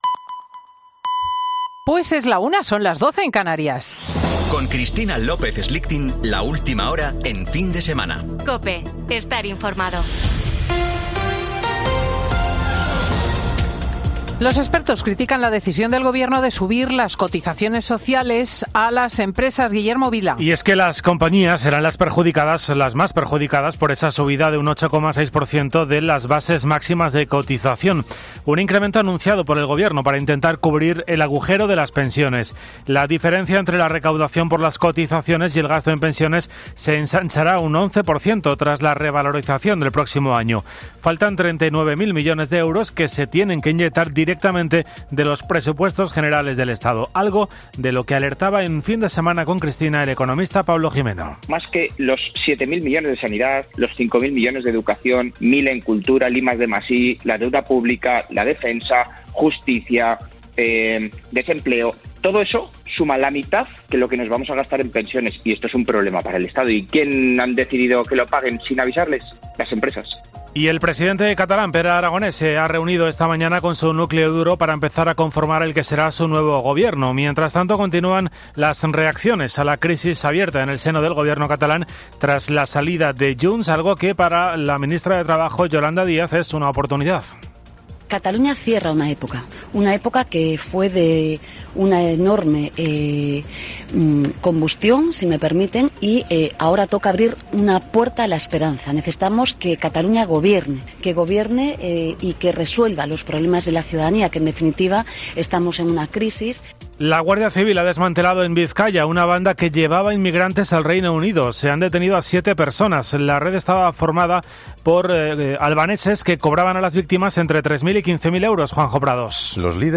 Boletín de noticias de COPE del 8 de octubre de 2022 a las 13.00 horas